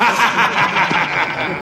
Evil laugh Free sound effects and audio clips
SHORT_DIABOLICAL_LAUGHTER_ZHu.wav